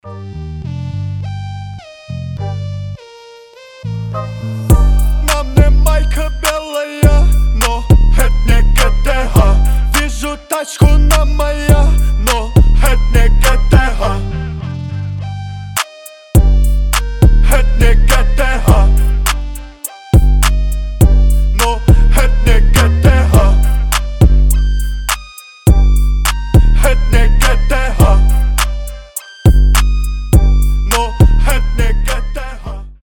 басы
качающие